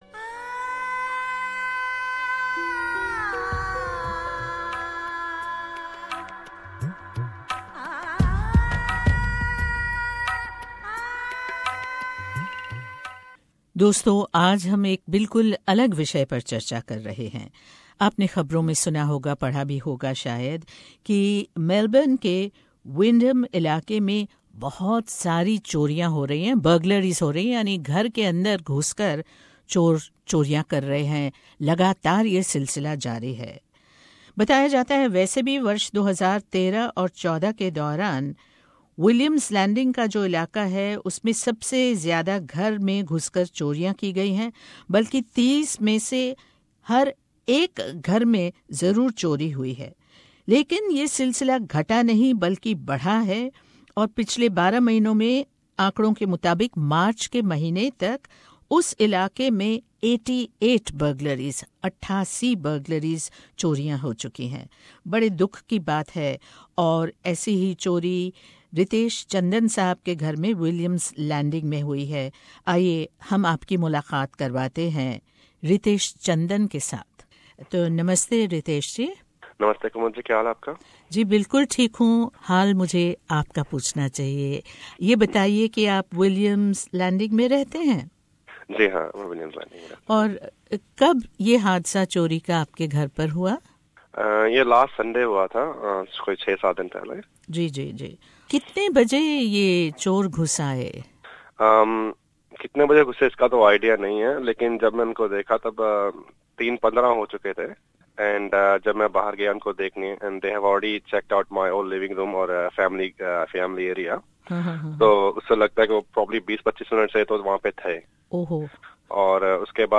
एक्सक्लूसिव भेंटवार्ता